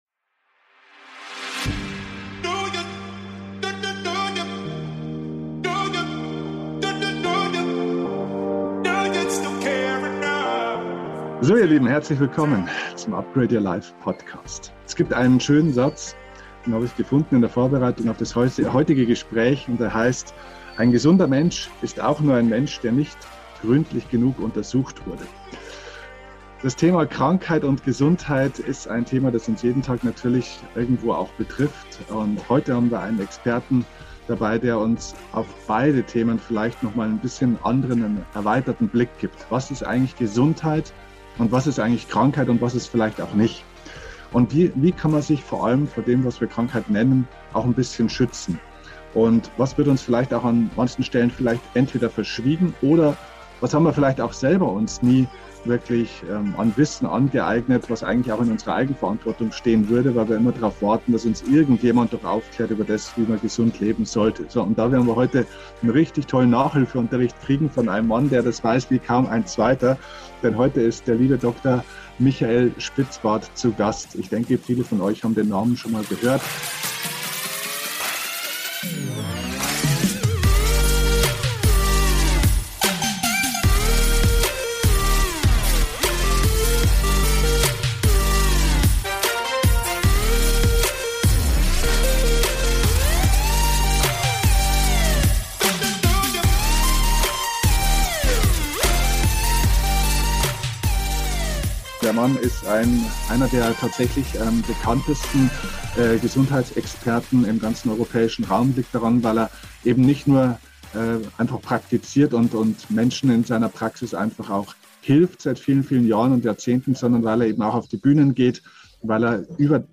Wohlbefinden vor 3 Jahren Interview mit einem der bekanntesten Gesundheitsexperten Europas 51 Minuten 42.12 MB